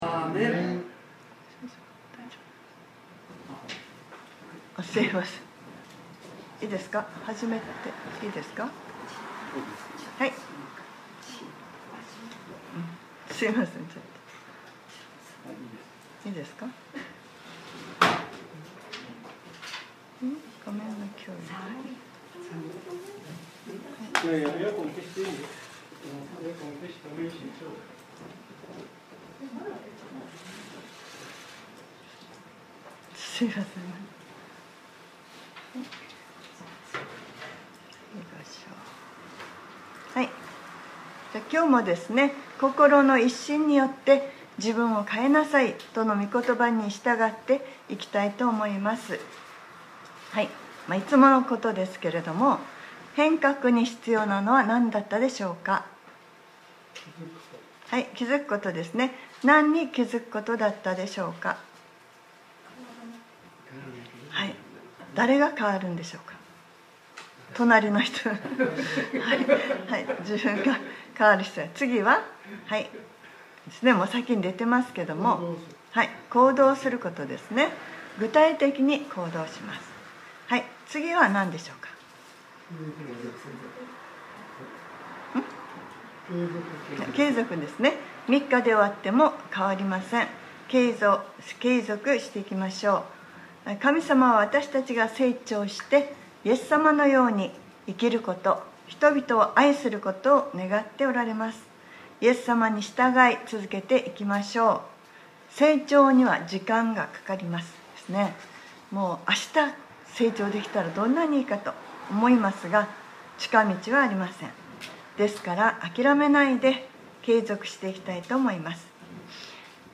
2021年05月16日（日）礼拝説教『 祝福を受ける』